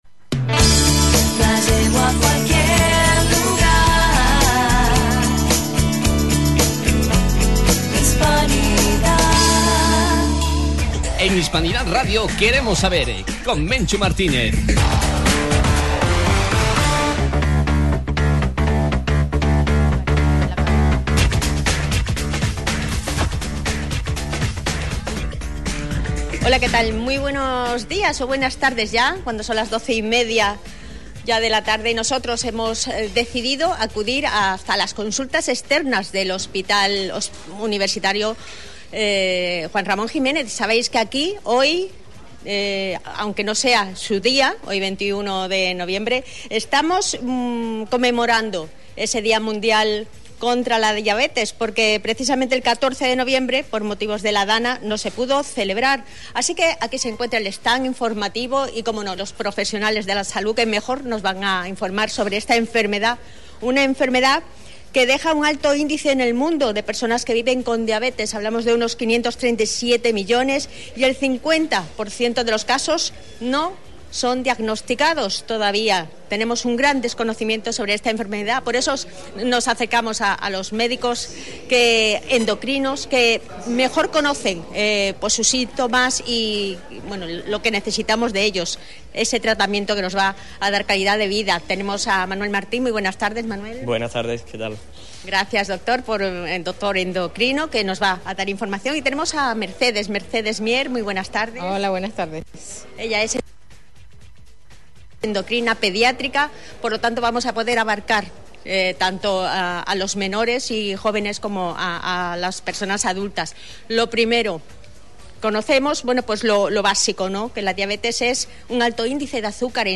Su Día Mundial es el 14 de noviembre, pero por la Dana no pudo Conmemorarse. Nos acercamos a una mesa informativa en el interior de las Consultas Externas.